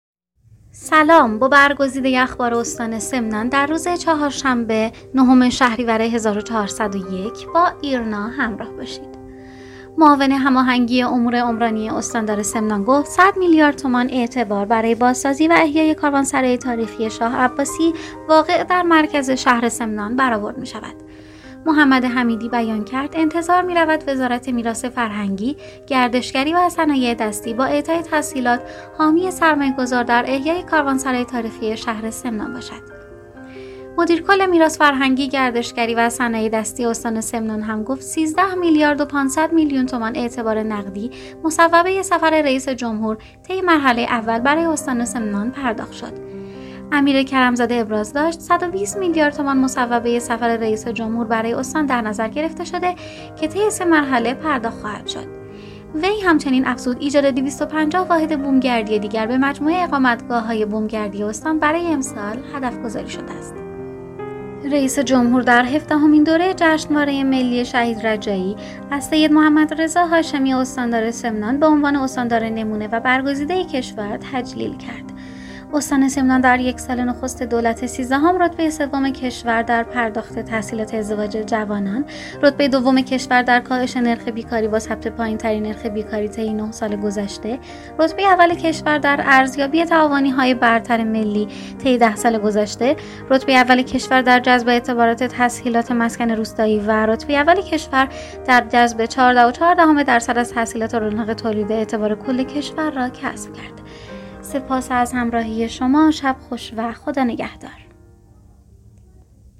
صوت | اخبار شبانگاهی نهم شهریور استان سمنان